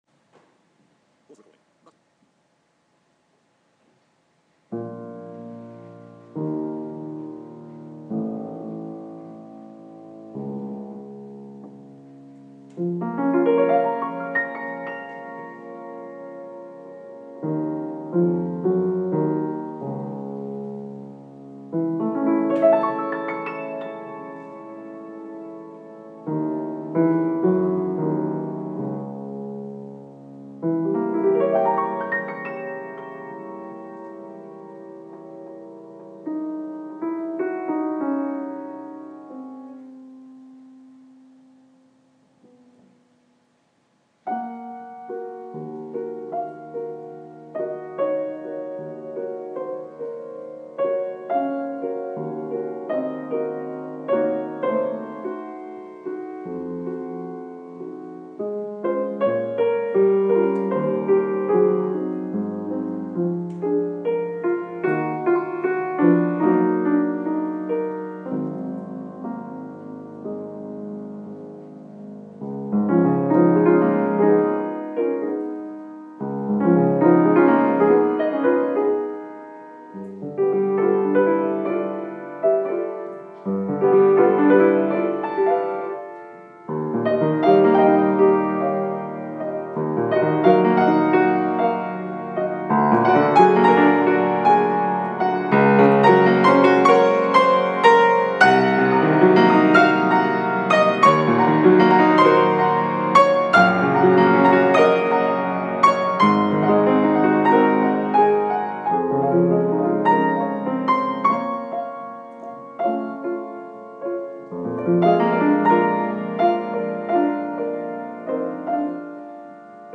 in d flat
i first heard this on my teacher's bechstein from 1904 but i much prefer a richer, warmer and mellower fragrance in the tone which is personally what bluthner provides.